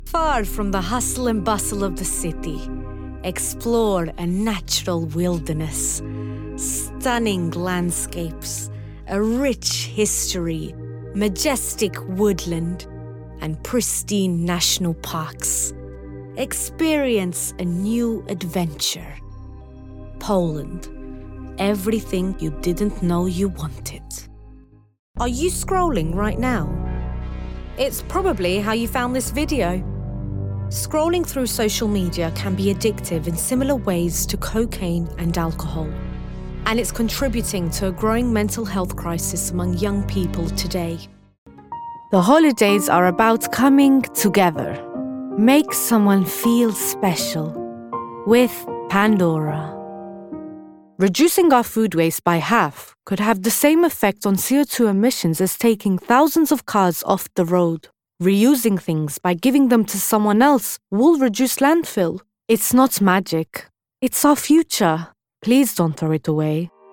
Albanian, Female, Home Studio, Teens-30s
English (Albanian Accent)